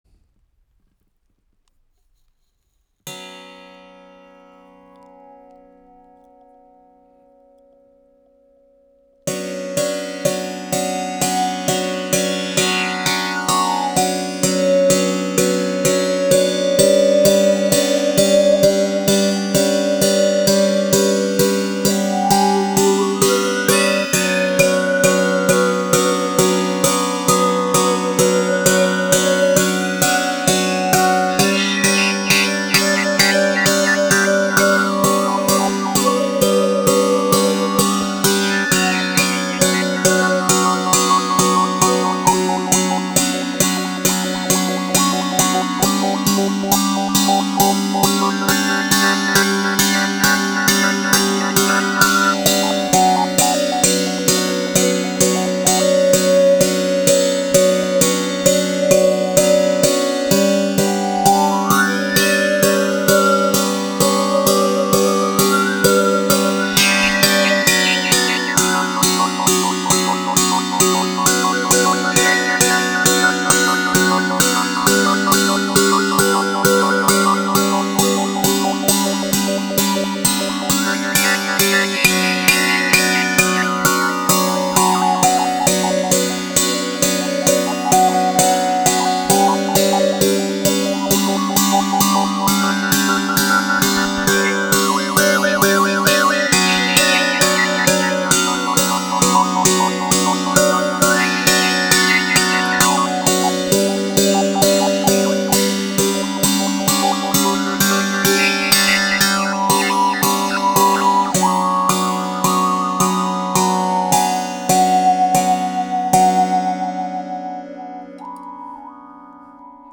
COSMICBOW 5 CORDES avec cuillère harmonique
Le son est très chaud et attrayant…entrainant.
Les sons proposés ici sont réalisés sans effet.